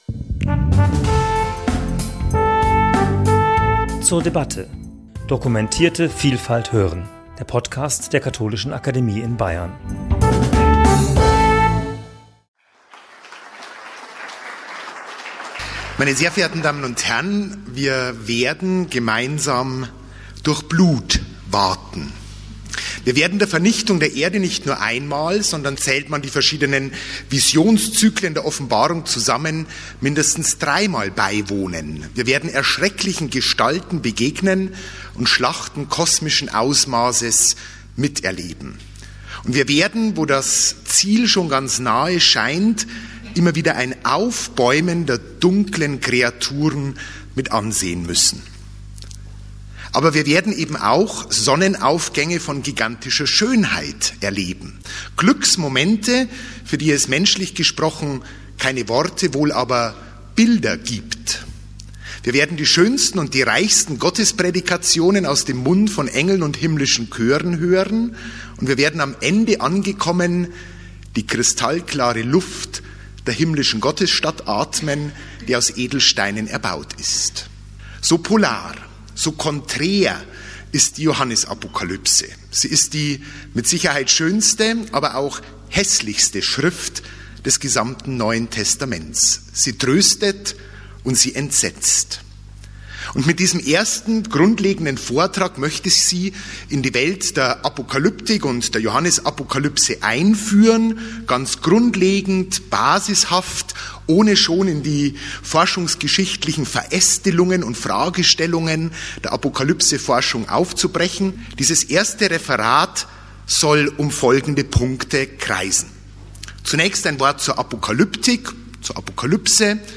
Das Einstiegsreferat fragt nach dem Autor, den Adressaten und dem zeitgeschichtlichen Hintergrund, aber auch nach hilfreichen Leseschlüsseln für ein sachgerechtes Verständnis der Apokalypse. Die Johannesapokalypse ist schön und schauerlich zugleich.